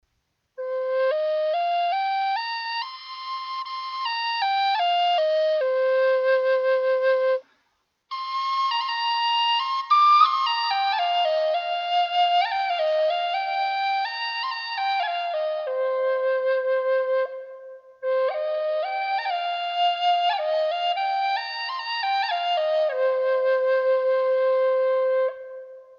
Western Cedar Native American Flutes
This is one of my favorite woods to make Native American Flutes out of because it is the most "mellow" sounding of all! 5 Hole Western Cedar Flues are also great Native American Flutes for beginners because the wood is so "forgiving" of the playing styles of new flute players.
$120 Key of High C